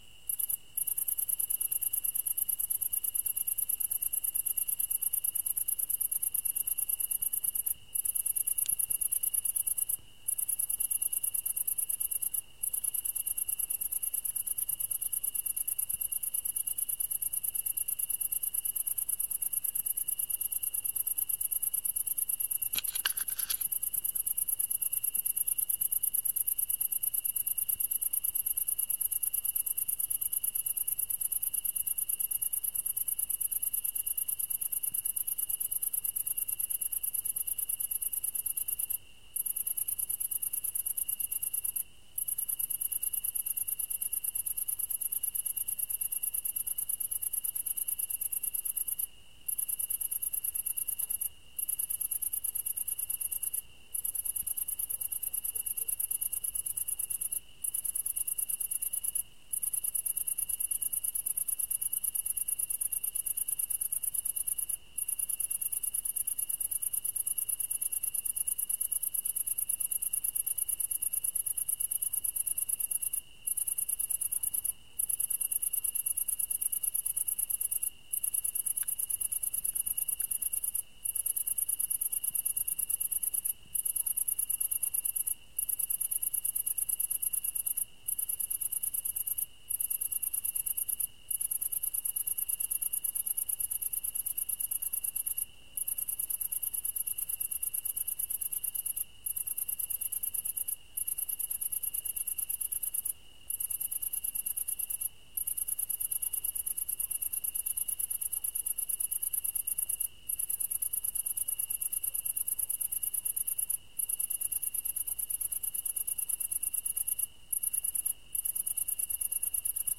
描述：日落之后，我把我的MP3播放器挂在树枝上，记录下这一氛围。文件录制于匈牙利的Kulcs（Dunaújváros附近的村庄）。
Tag: 环境 板球 现场记录 恐怖 性质 夜晚 惊悚